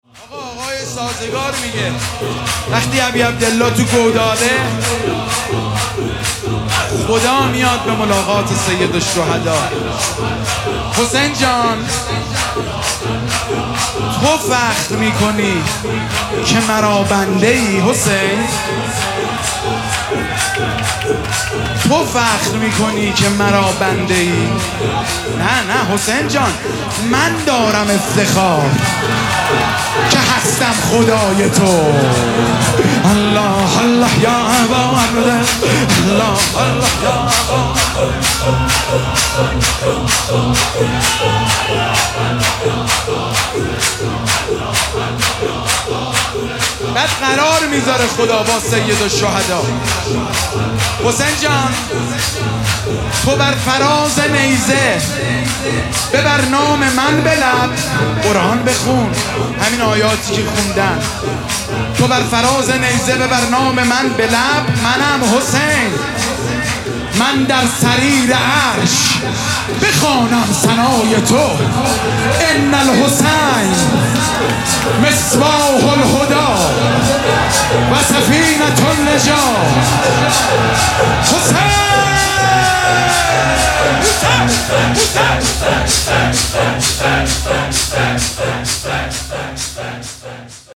مداحی
در شب پنجم محرم 1402